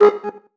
error-notification-pop-in.wav